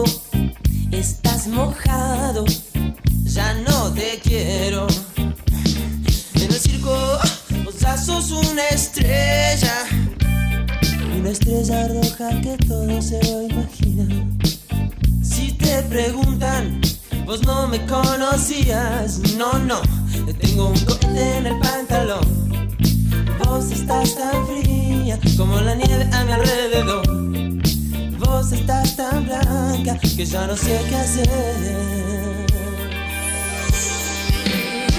Жанр: Рок / Альтернатива